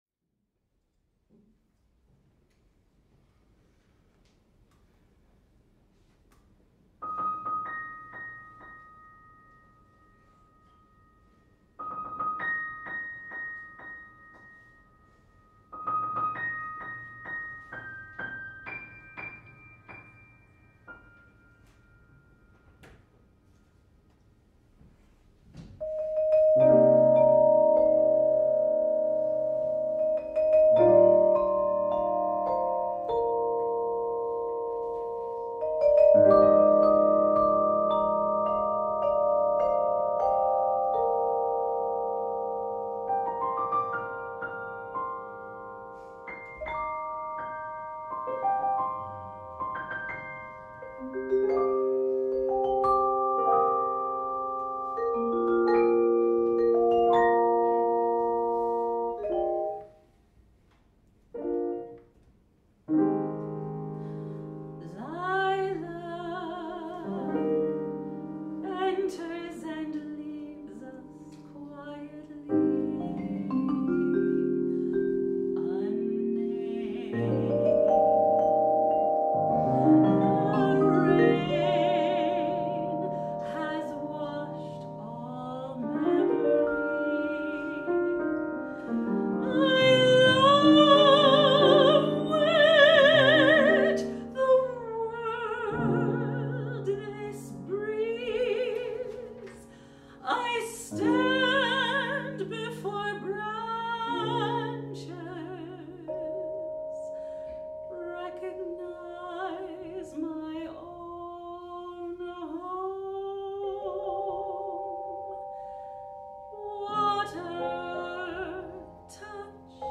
Chamber Music , Individual Art Songs
Soprano